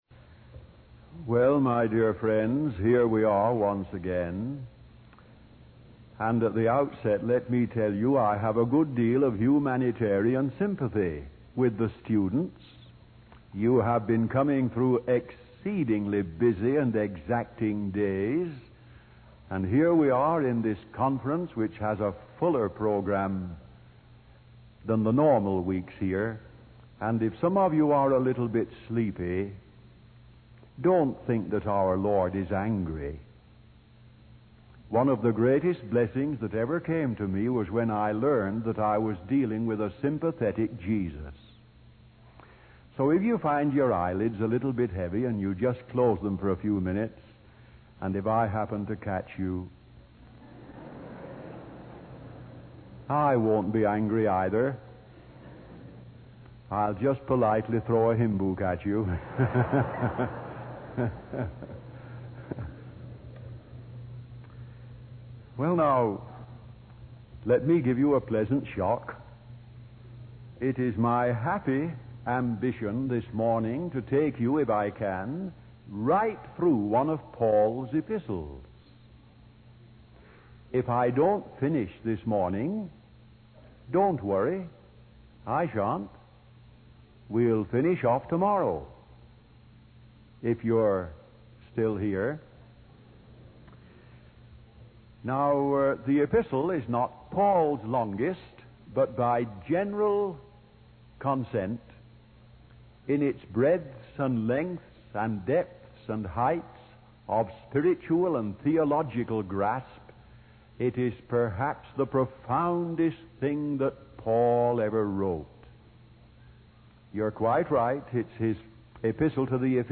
In this sermon, the speaker discusses five important aspects of the Christian faith.